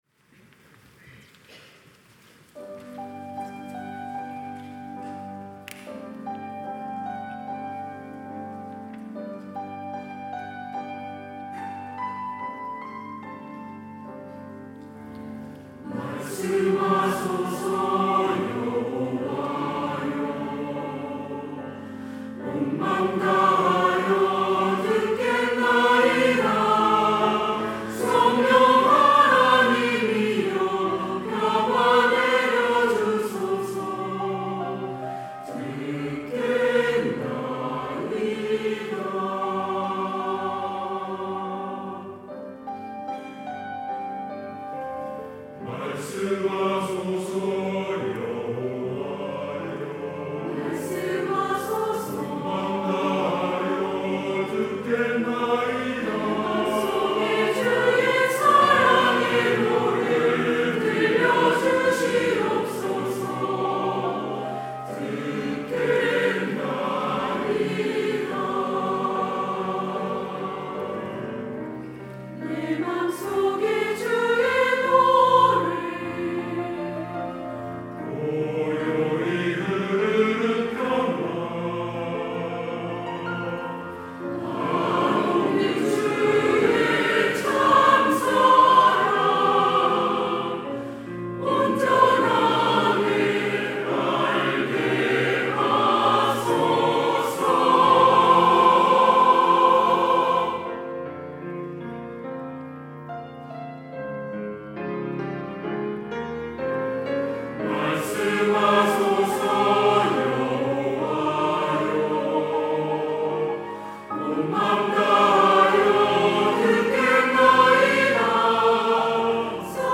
시온(주일1부) - 말씀하소서 여호와여
찬양대